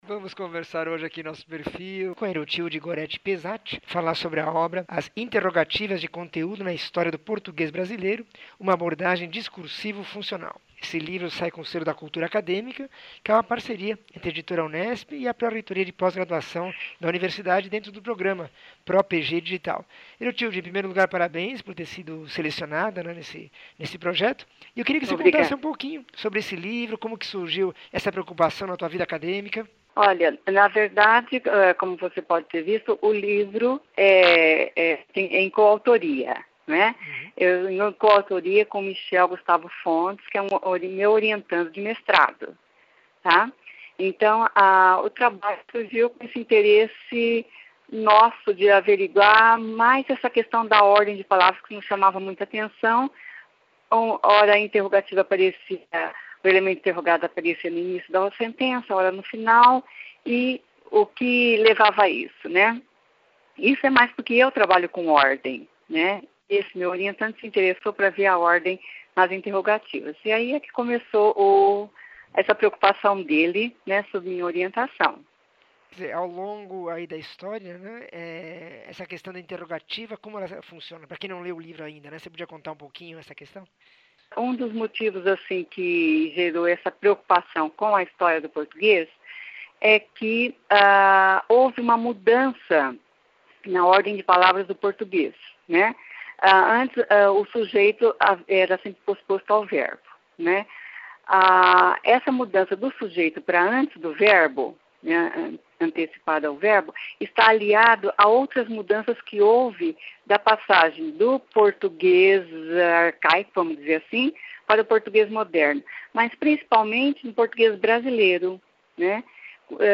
entrevista 1766